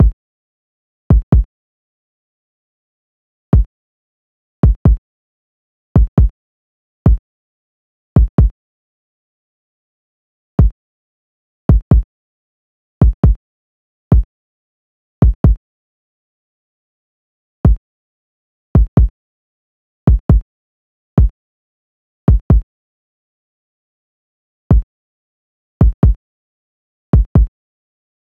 rack kick1 (tm).wav